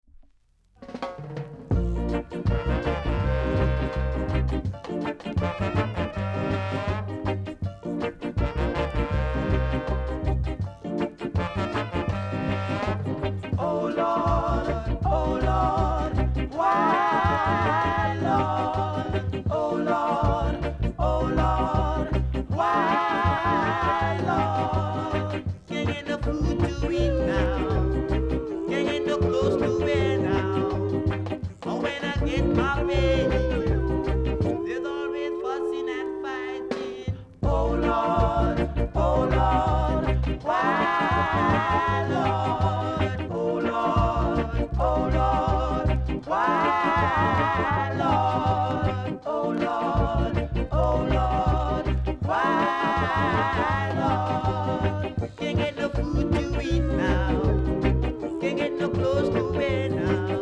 ※小さなチリノイズが少しあります。
コメント NICE SKINS VOCAL & INST!!